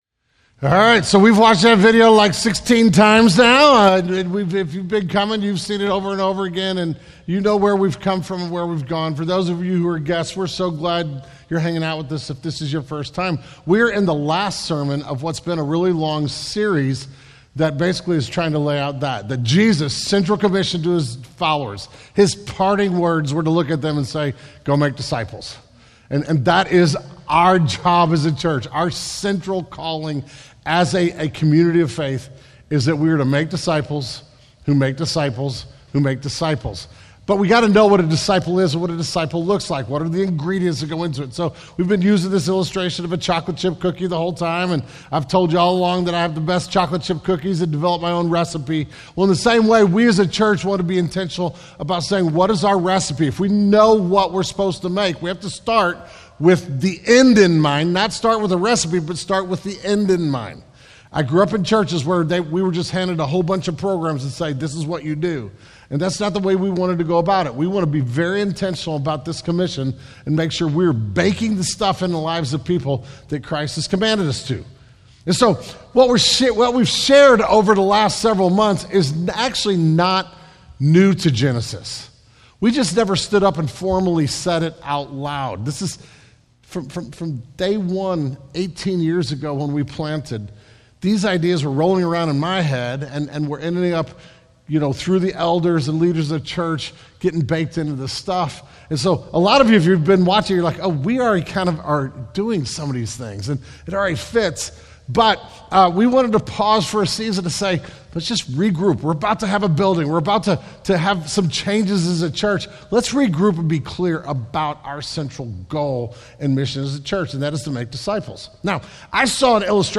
The May 2024 Sermon Audio archive of Genesis Church.